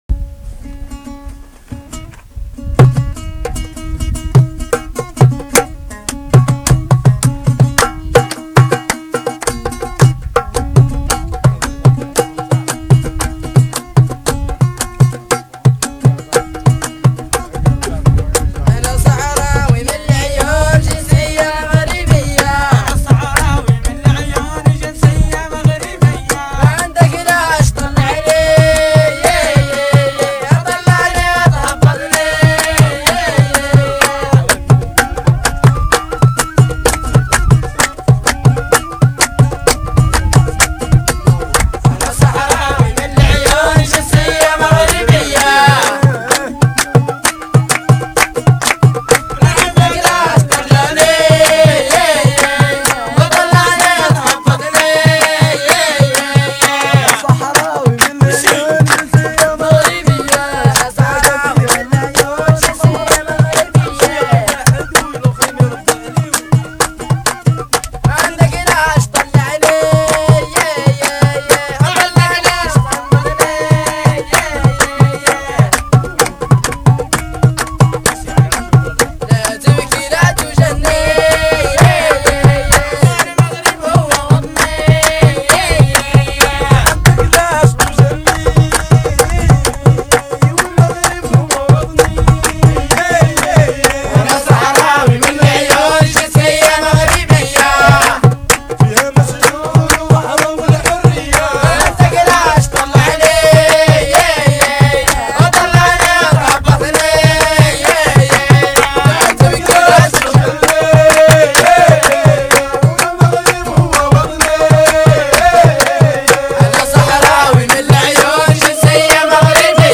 Groupe de musique
des jeunes sahraouis
Cet enregistrement a été réalisé dans le désert sous une tente nomade en mai 2003.
Les chants sont le témoignage de la culture musicale sahraouis actuelle.